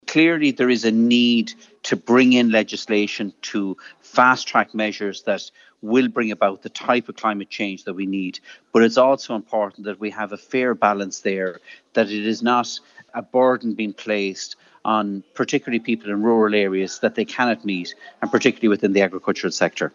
Independent TD and former Environment Minister, Denis Naughten, says it has “a number of flaws”……………..